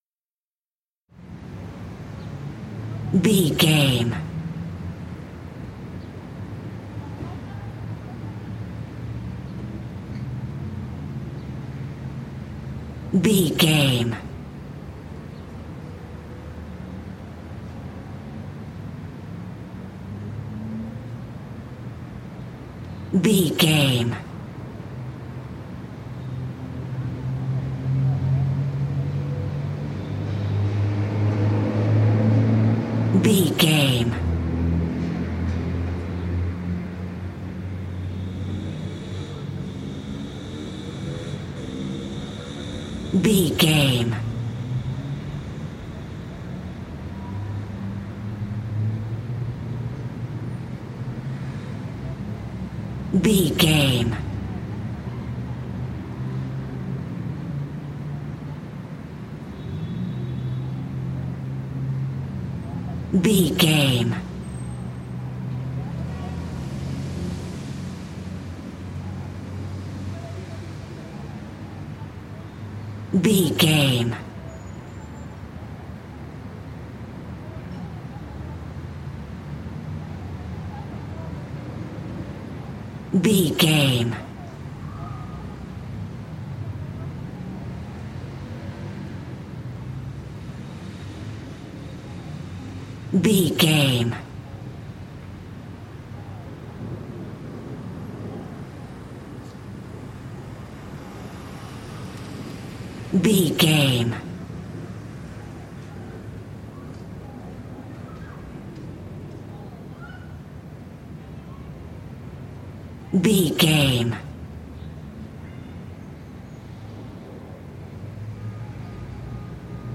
City calm street
Sound Effects
urban
ambience